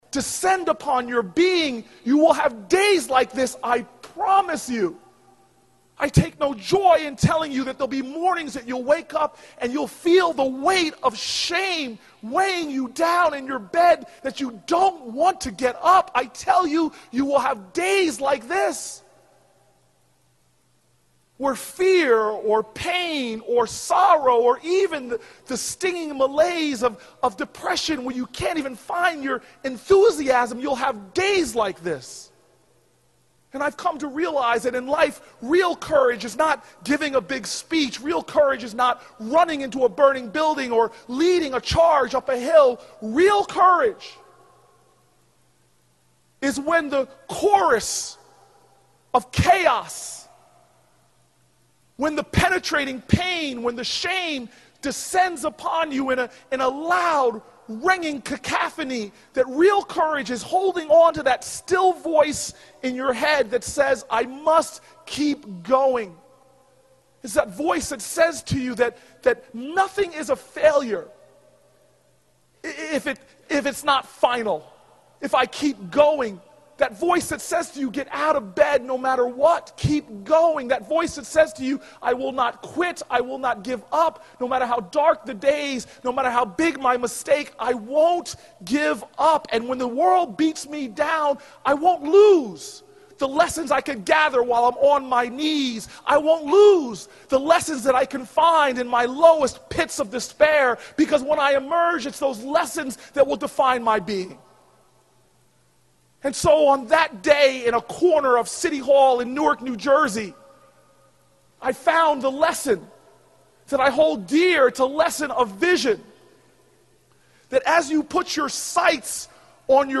公众人物毕业演讲 第450期:科里布克2013年耶鲁大学(18) 听力文件下载—在线英语听力室